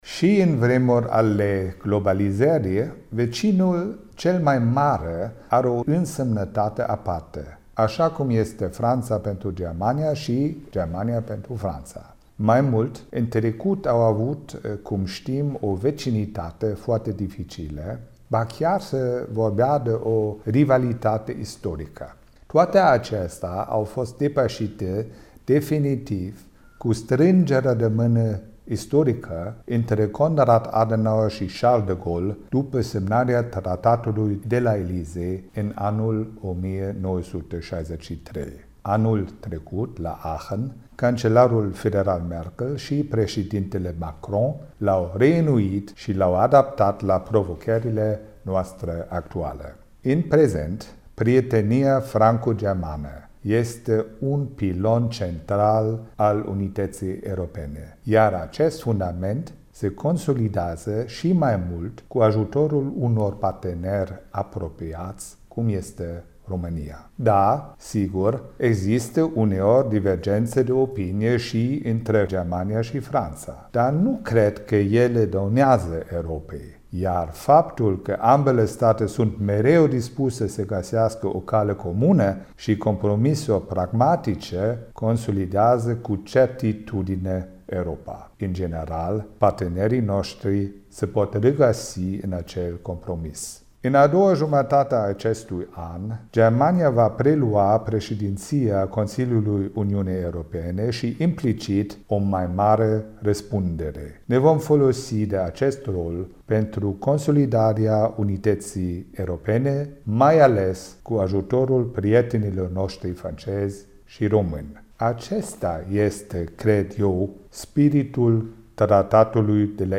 Excelența Sa Cord Meier-Klodt, Ambasadorul Germaniei la București:
Ambasadorul-Germaniei-Cord-Meier-Kloth.mp3